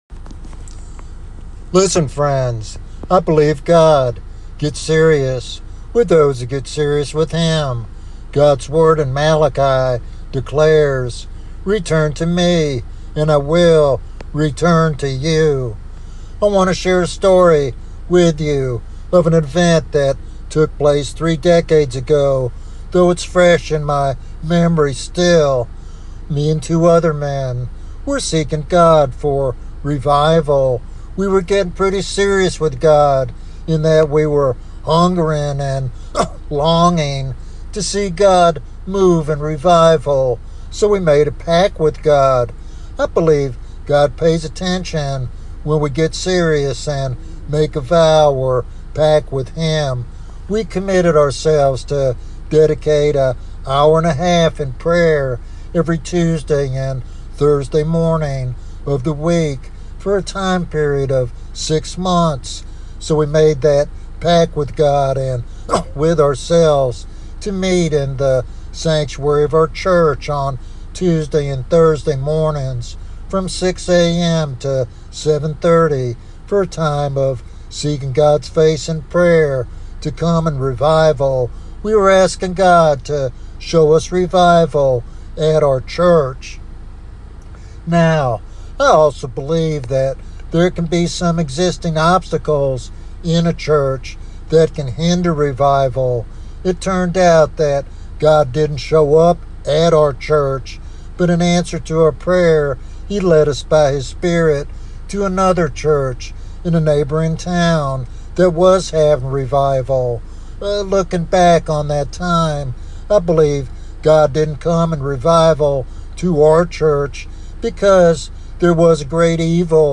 In this compelling sermon